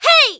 qyh普通1.wav 0:00.00 0:00.40 qyh普通1.wav WAV · 34 KB · 單聲道 (1ch) 下载文件 本站所有音效均采用 CC0 授权 ，可免费用于商业与个人项目，无需署名。
人声采集素材